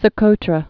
(sə-kōtrə)